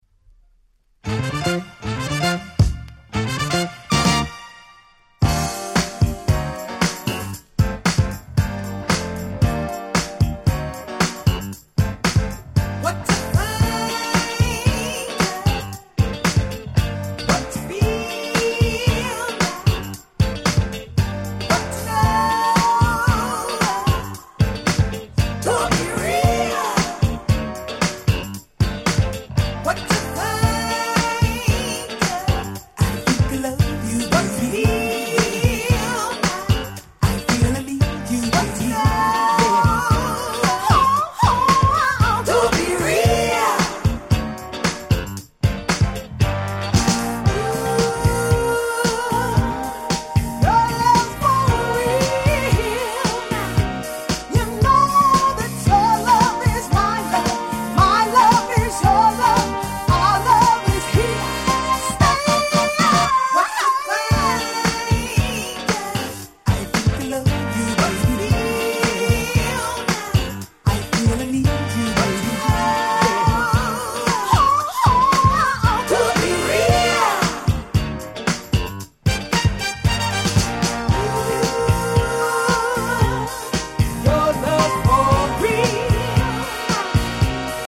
基本的に原曲に忠実ですが、良く聴いてみると所々にオシャレなアレンジが施されており素敵です！